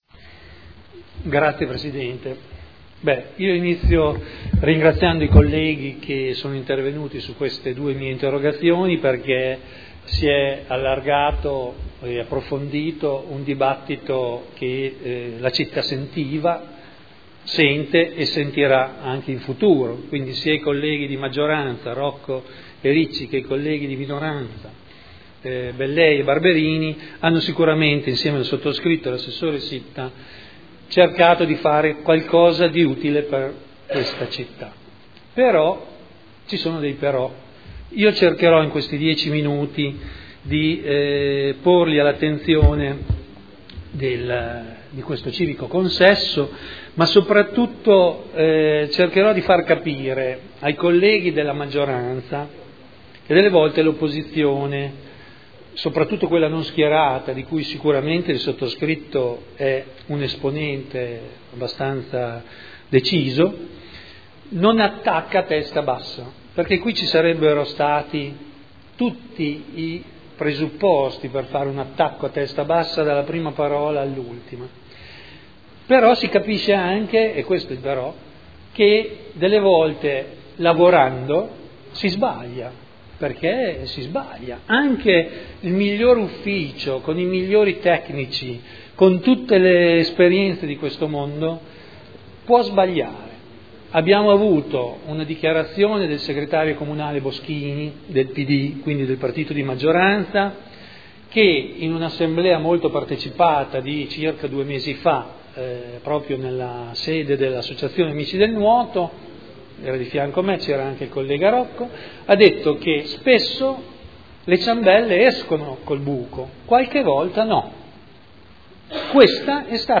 Seduta del 05/09/2011.
Seduta del 5 settembre 2011